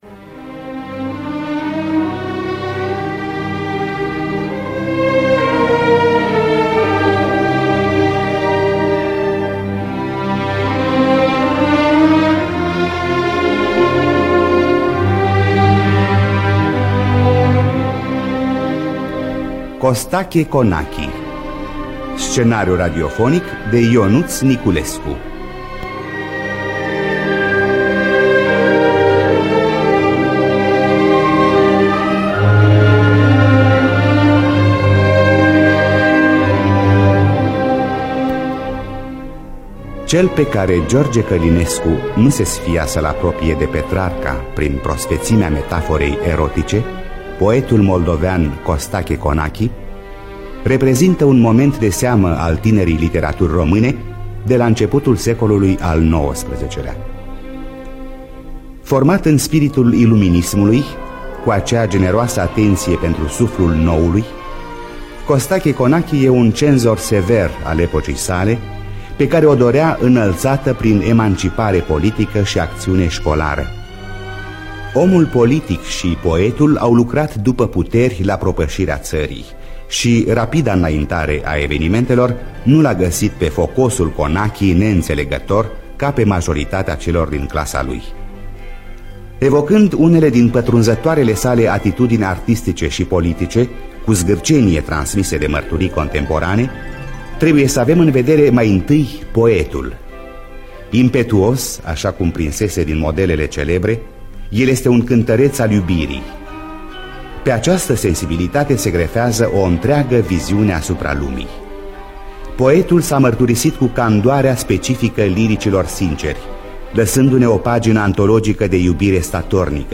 Scenariu radiofonic de Ionuț Niculescu.